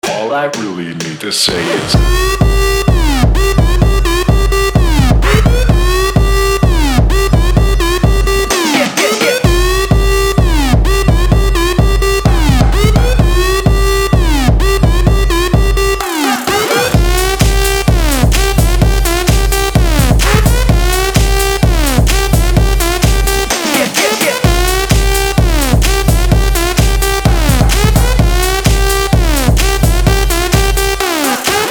Trap
club